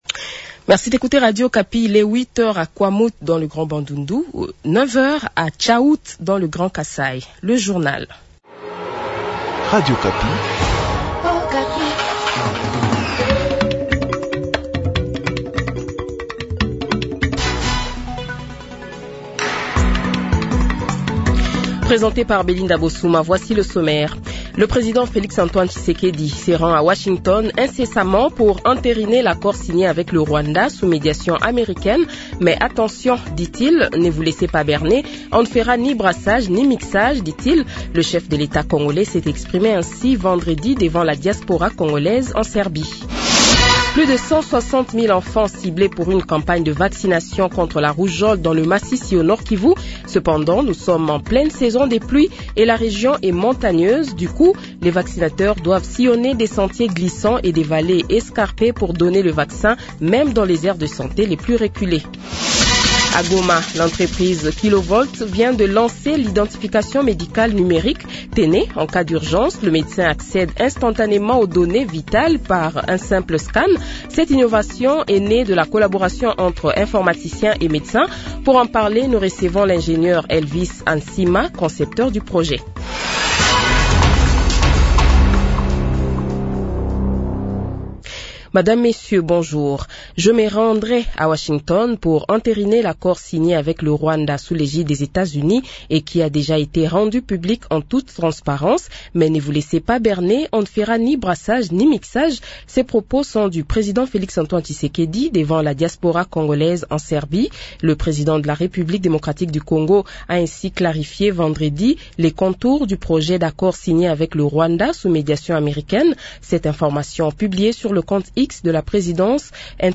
Le Journal de 7h, 30 Novembre 2025 :